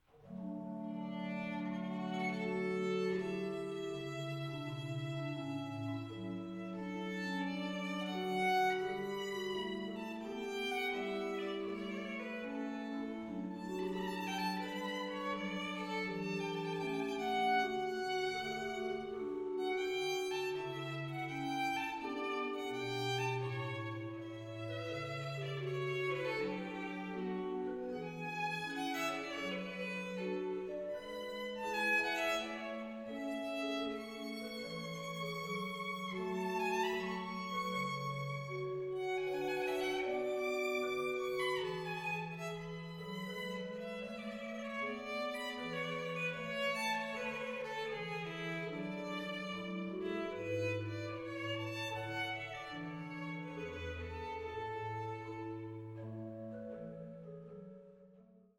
Klassische Kirchenkonzerte
Der Programmschwerpunkt ihrer Konzerte liegt auf Barockmusik, insbesondere von J.S. Bach und G.F. Händel, für Violine und Orgel sowie auf gesungenen Gebeten und Werken anderer Komponisten wie F. Mendelssohn, W.A. Mozart und J. Rheinberger.